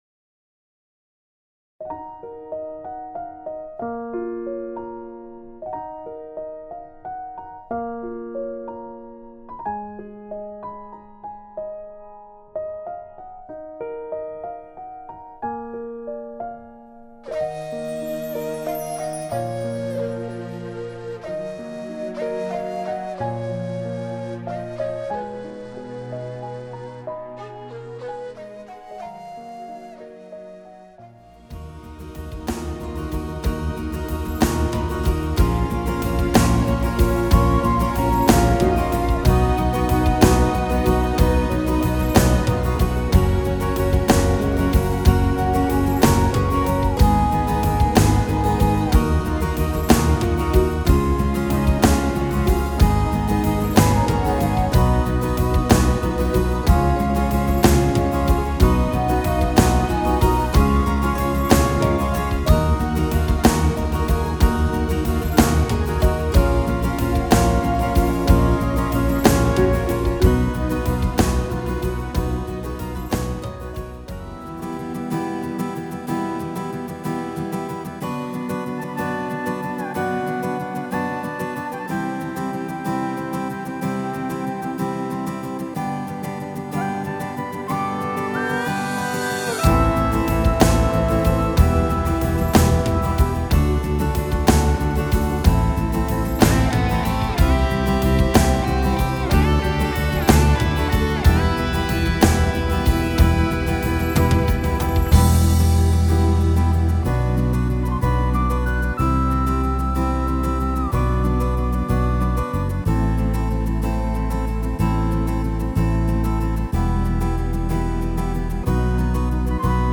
• Eb
תואם מקור במבנה. אך עם עיבוד שונה